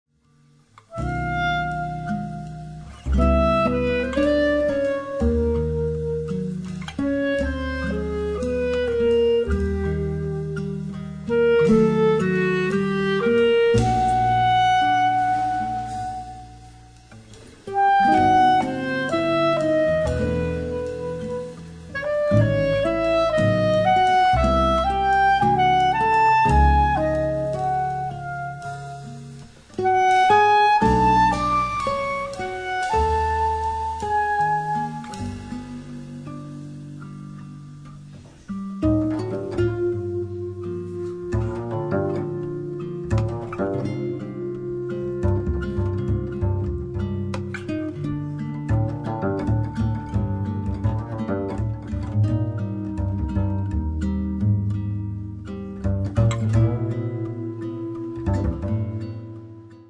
Sax tenore e soprano, clarinetto
Chitarra classica
Contrabbasso
Batteria